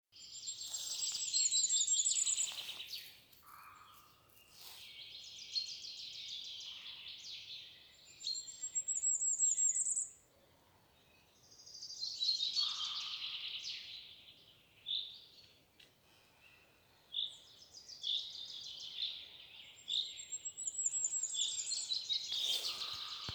Putni -> Ķauķi ->
Sārtgalvītis, Regulus ignicapilla
StatussDzied ligzdošanai piemērotā biotopā (D)